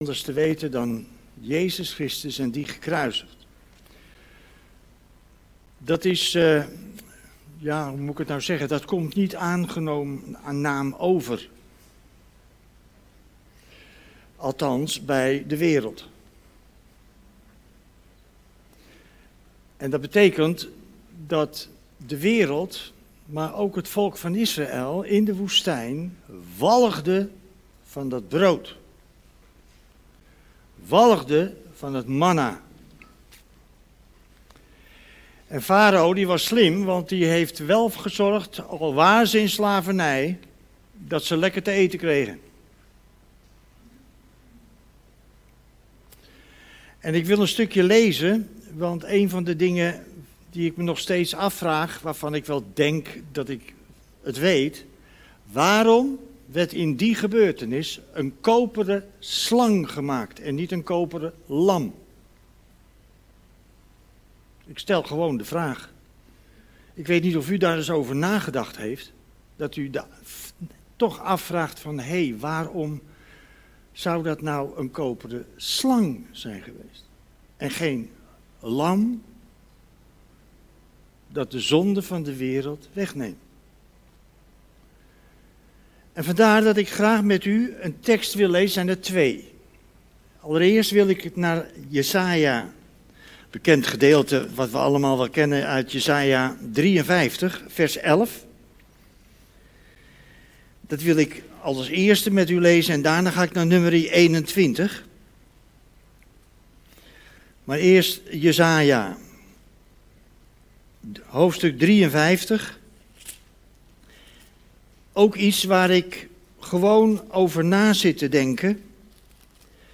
Geplaatst in Preken